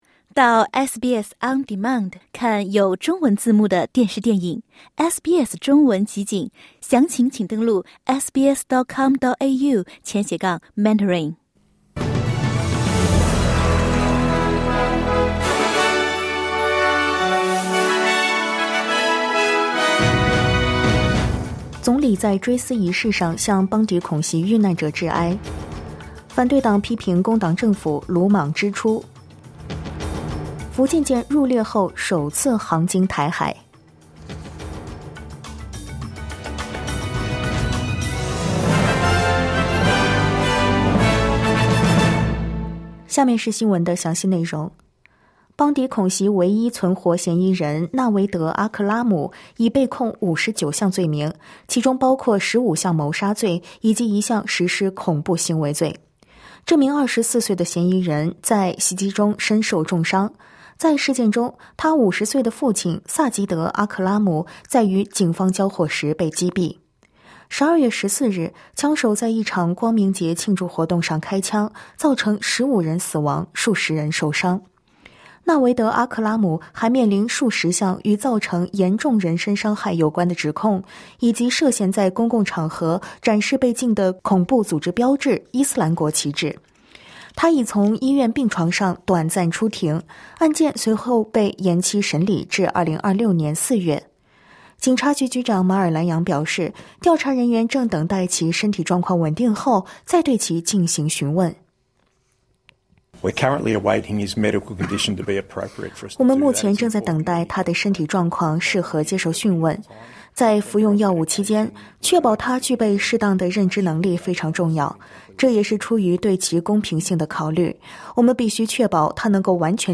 SBS早新闻（2025年12月18日）